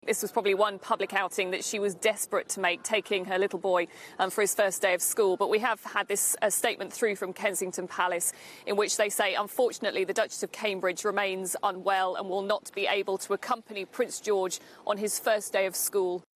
outside Kensington Palace.